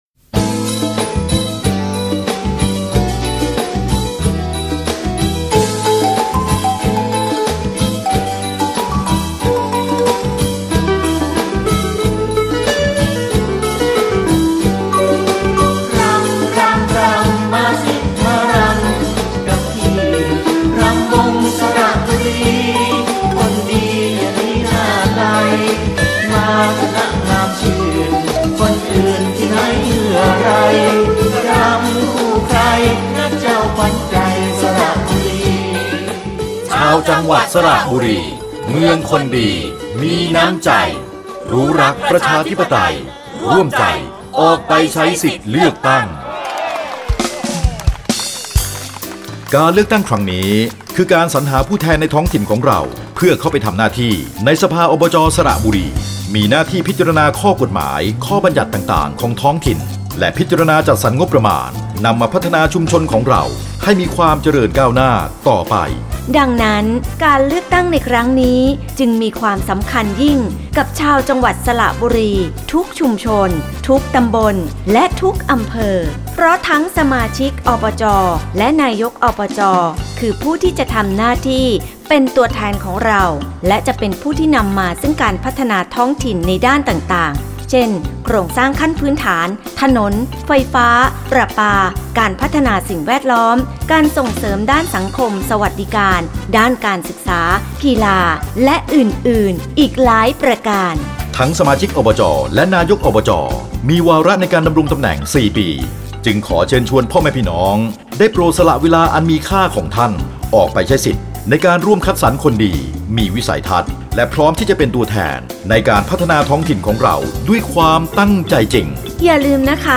สปอตประชาสัมพันธ์ การเลือกตั้งสมาชิกสภาองค์การบริหารส่วนจังหวัดสระบุรีและนายกองค์การบริหารส่วนจังหวัดสระบุรี 1 – (ไฟล์เสียง MP3)
สปอต_รณรงค์เลือกตั้ง_อบจ_สระบุรี_Vol_1.mp3